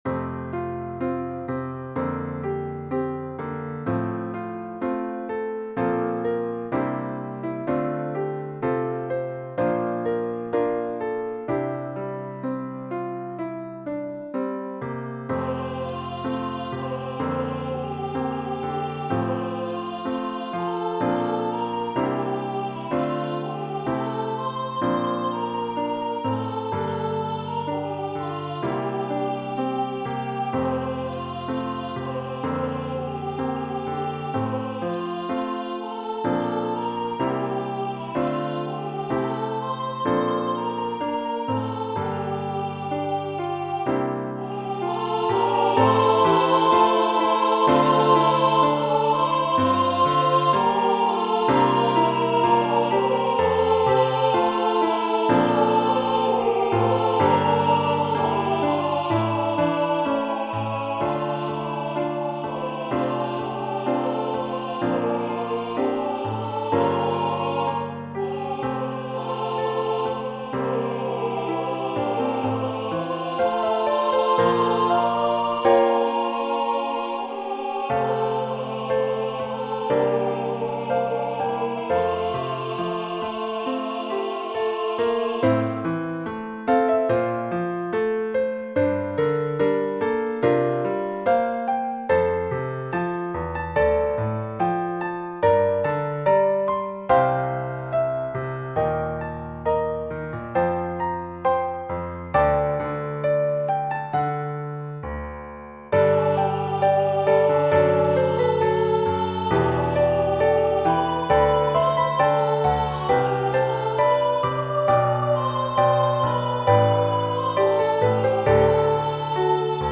When the Savior Comes Again Hymn #1002 SSA with Piano Accompaniment
Voicing/Instrumentation: SSA